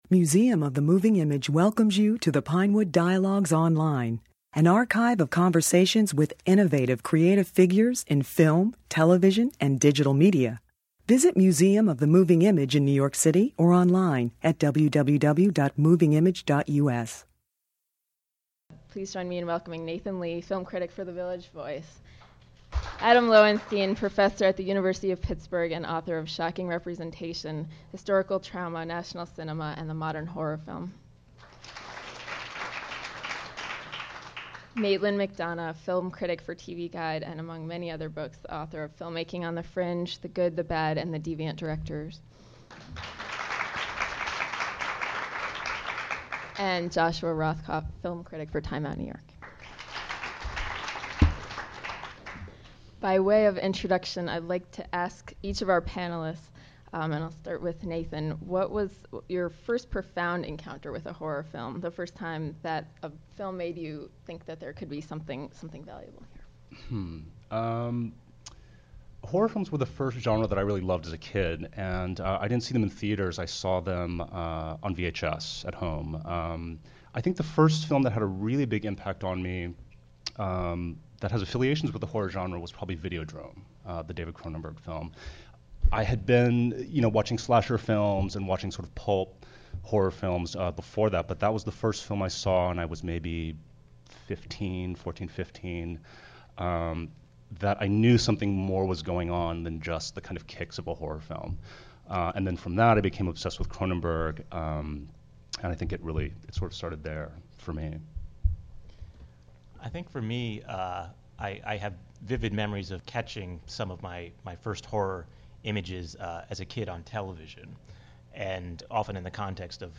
Considering Horror Panel June 17, 2007
This discussion brought together critics and scholars to talk about the films shown in the Museum's groundbreaking series It's Only a Movie: Horror Films from the 1970s and Today.